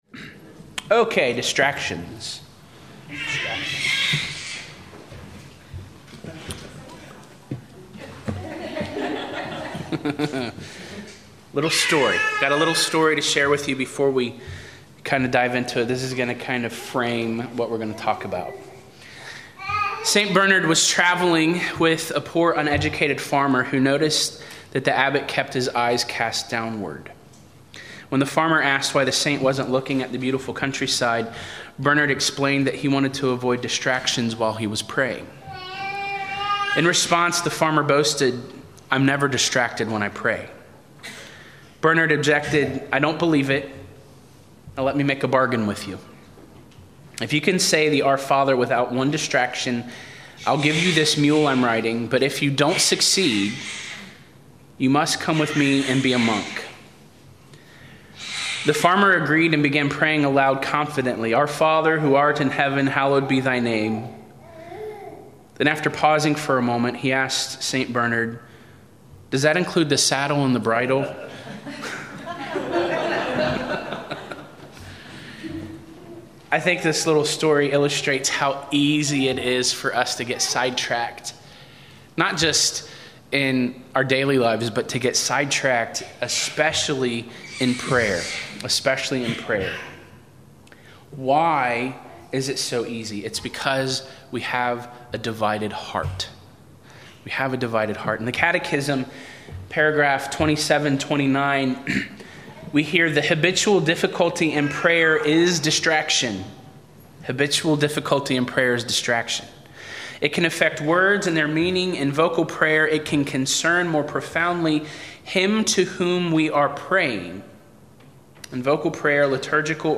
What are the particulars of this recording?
the last of five talks in his weekend retreat